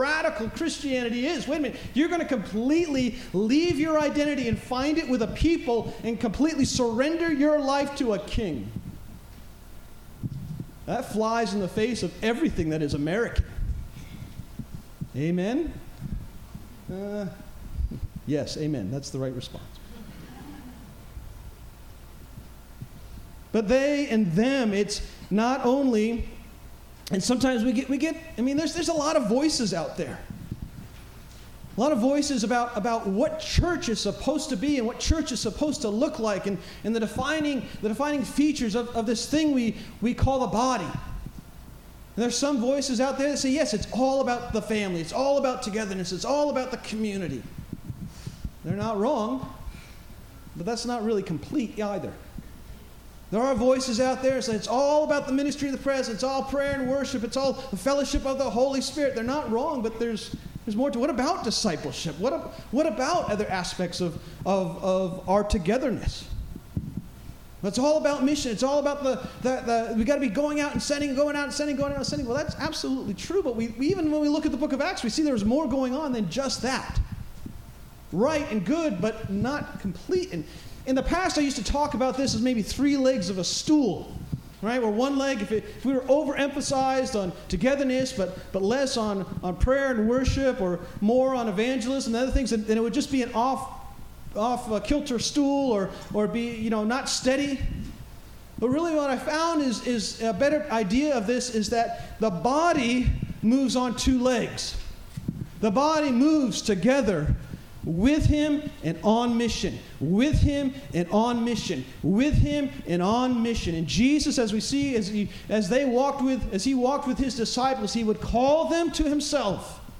Together With Him On His Mission Stand-alone Sermon Preached by